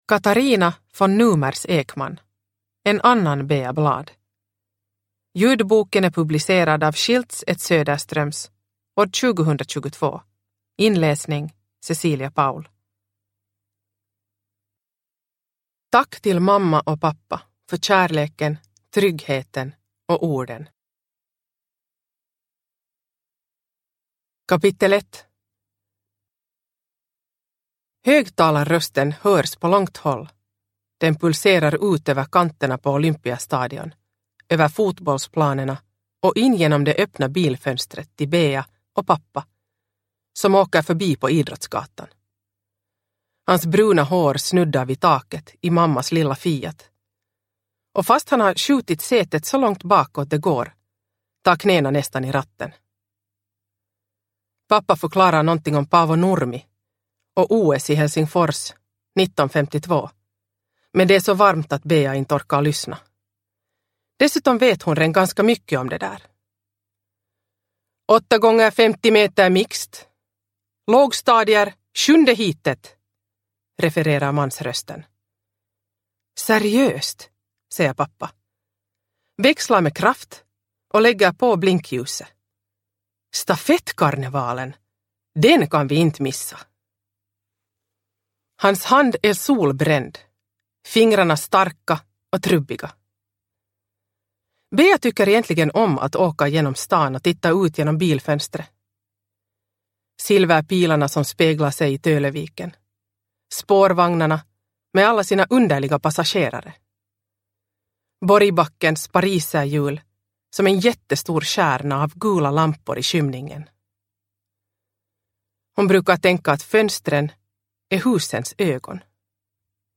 En annan Bea Bladh – Ljudbok – Laddas ner